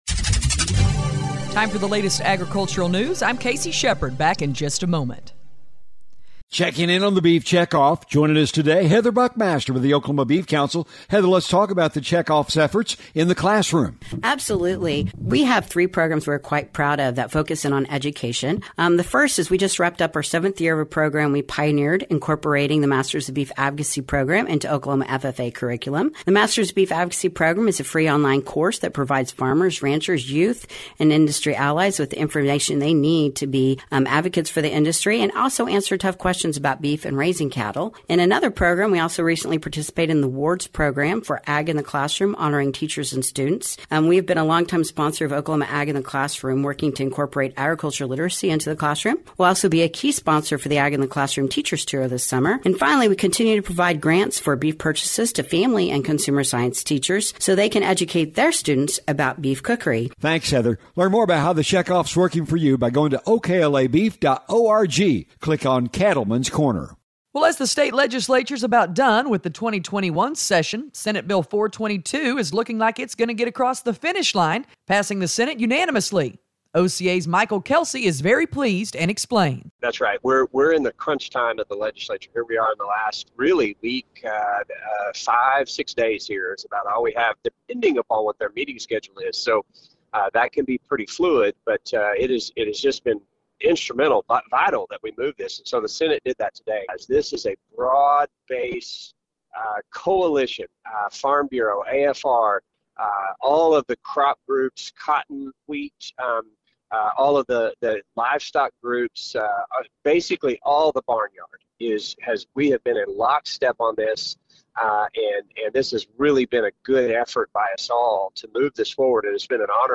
Agricultural News